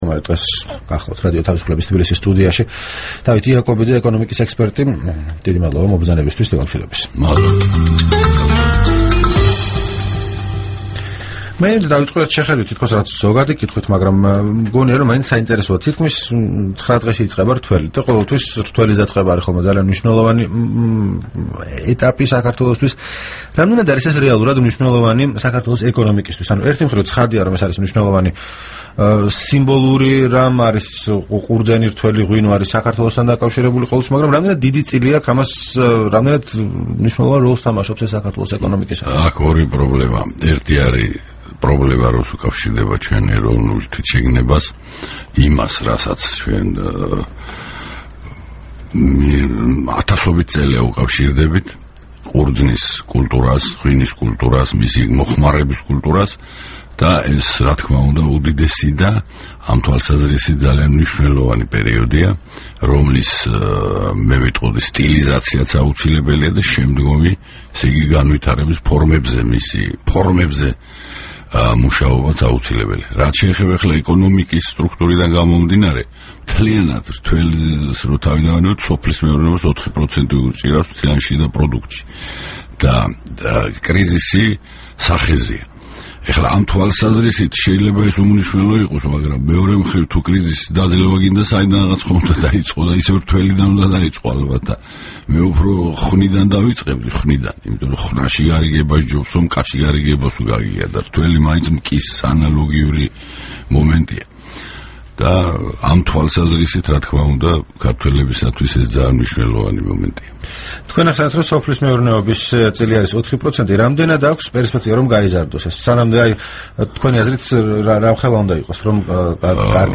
რადიო თავისუფლების თბილისის სტუდიაში სტუმრად იყო ეკონომიკის ექსპერტი